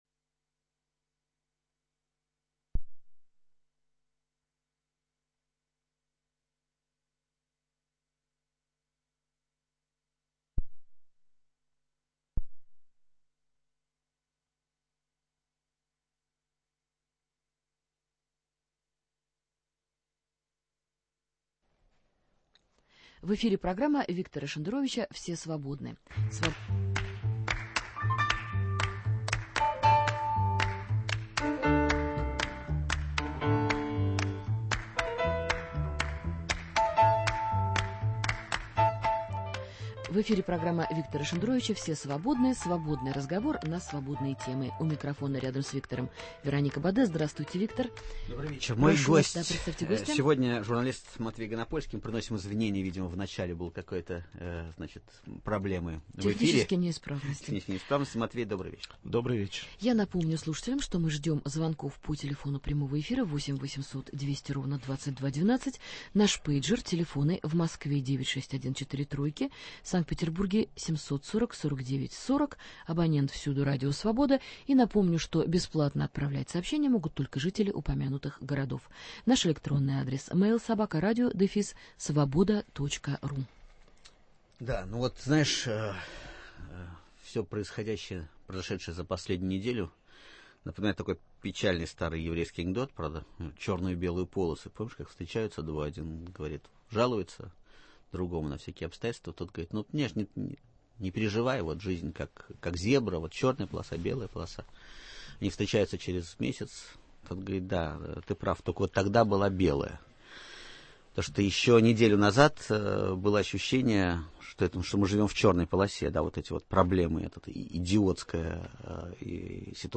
Свободный разговор на свободные темы. Гостей принимает Виктор Шендерович, который заверяет, что готов отвечать на любые вопросы слушателей, кроме двух: когда он, наконец, уедет в Израиль и сколько он получает от ЦРУ?